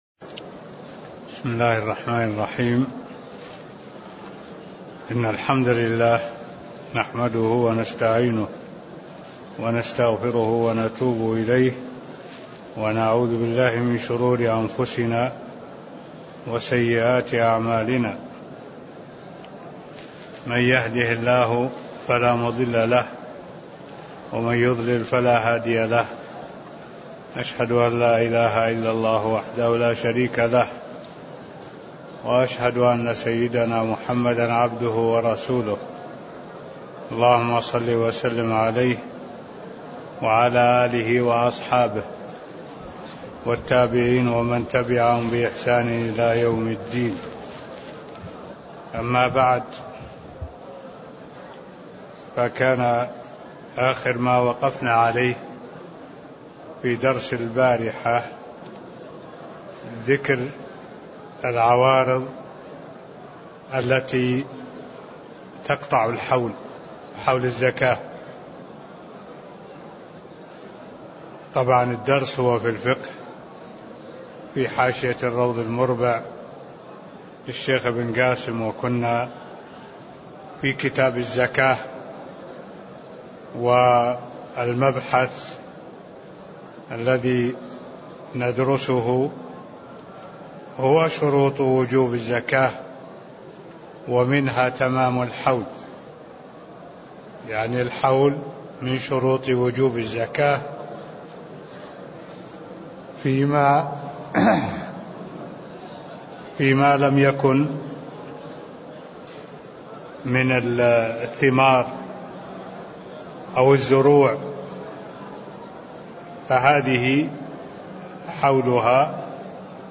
تاريخ النشر ٢١ محرم ١٤٢٧ هـ المكان: المسجد النبوي الشيخ: معالي الشيخ الدكتور صالح بن عبد الله العبود معالي الشيخ الدكتور صالح بن عبد الله العبود ذكر العوارض التي تقطع حول الزكاة (005)